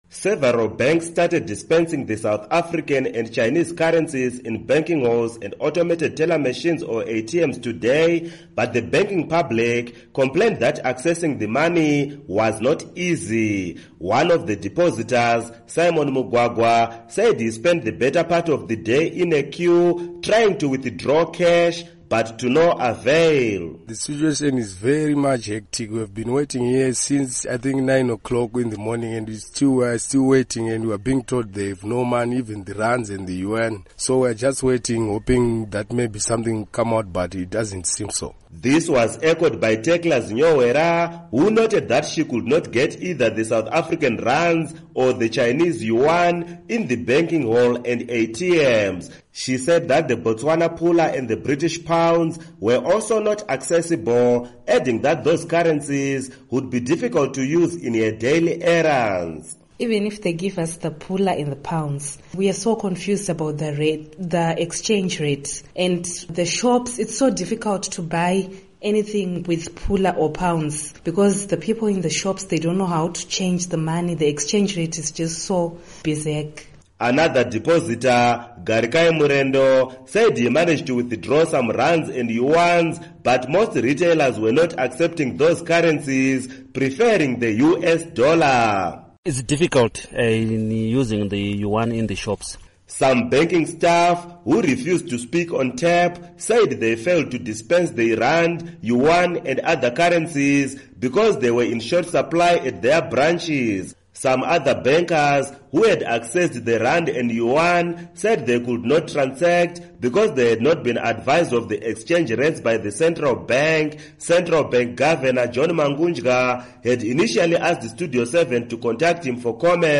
Report on Cash Shortages